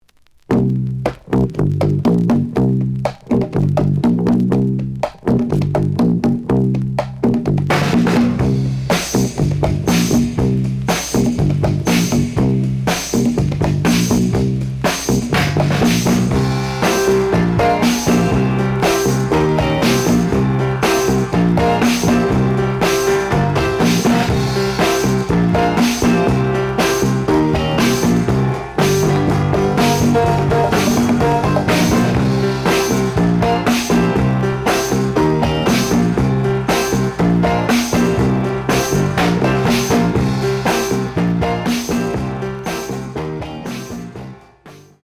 The audio sample is recorded from the actual item.
●Genre: Soul, 60's Soul
Some noise on parts of both sides.)